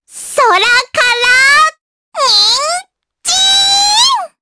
Luna-Vox_Skill7_jp.wav